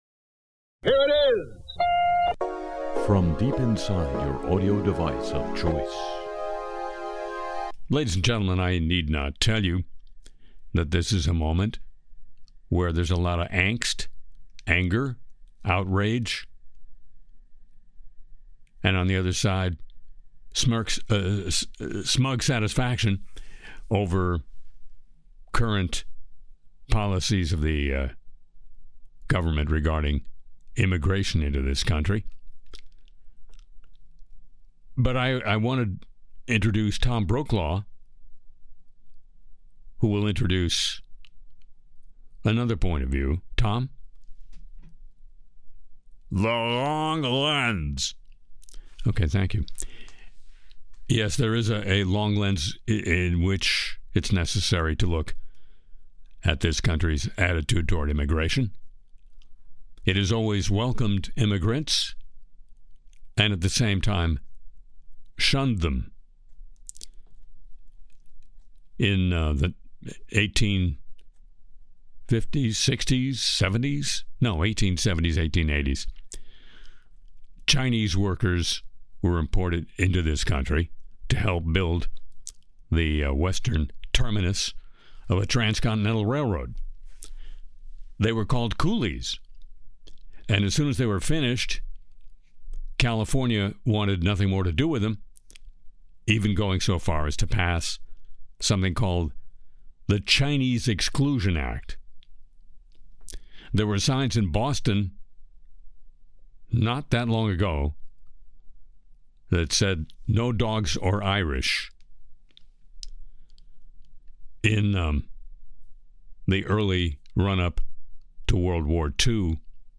Music & Segments